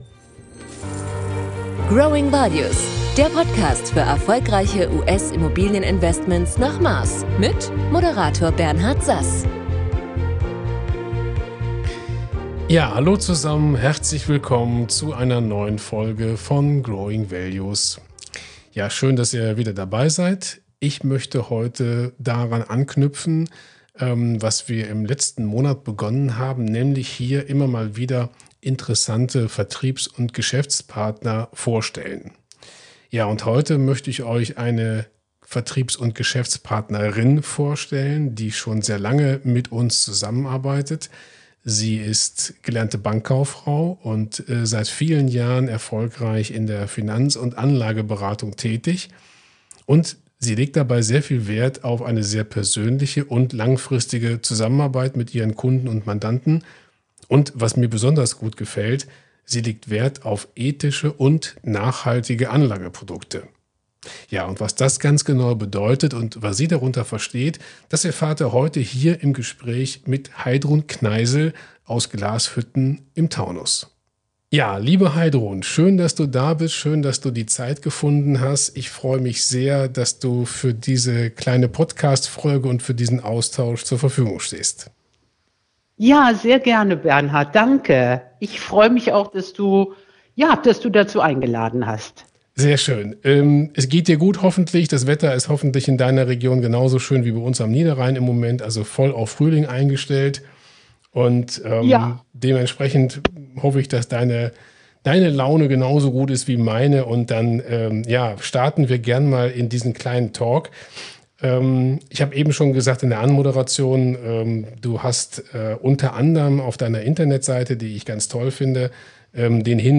In der aktuellen Folge unseres Podcast kommt eine weitere Vertriebs- und Geschäftspartnerin zu Wort, die bereits seit vielen Jahren in der Finanzberatung erfolgreich tätig ist und zu unseren treuen und langjährigen Partnerinnen zählt.